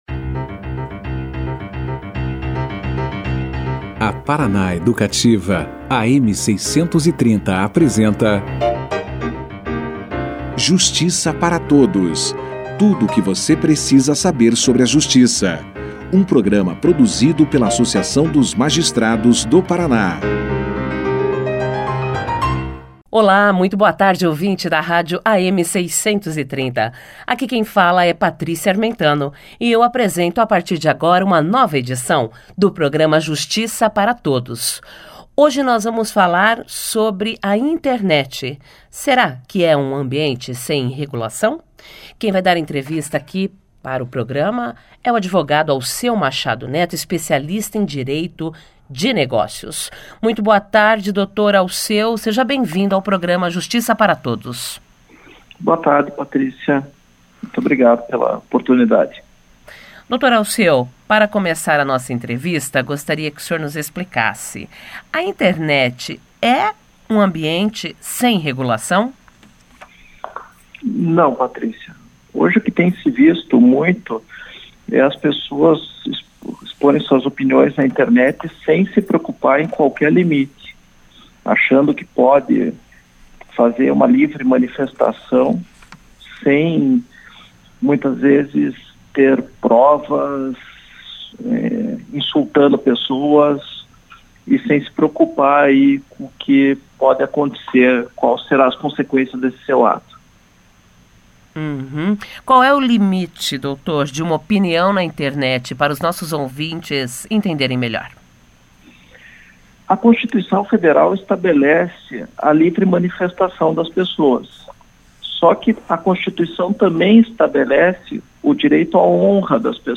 O convidado falou a respeito da revolução nas relações pessoais causada pelas ferramentas tecnológicas e fez um alerta para os usuários entenderem que não podem manifestar opiniões na internet inconsequentemente. Na oportunidade, ele também explicou de que forma a Constituição assegura a livre manifestação de pensamento e expressão e o que significa falar em crimes de honra em ambientes virtuais. Confira aqui a entrevista íntegra.